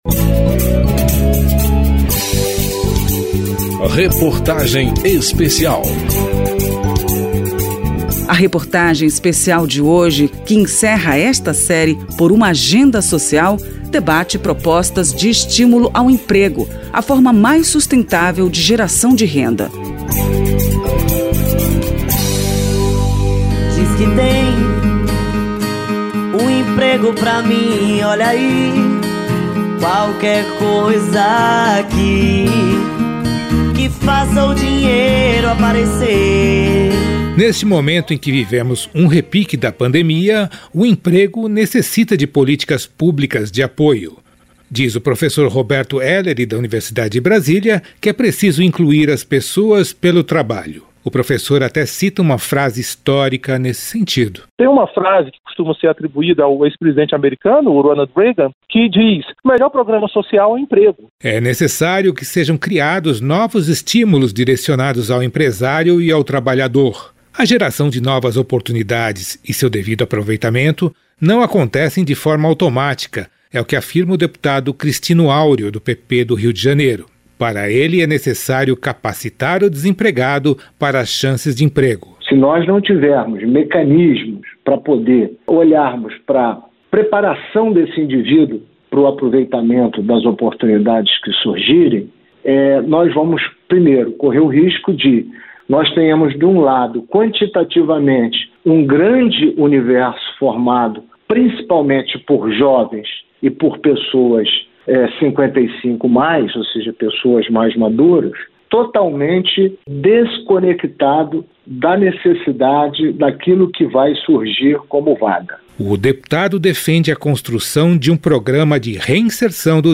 Reportagem Especial
Entrevistas neste capítulo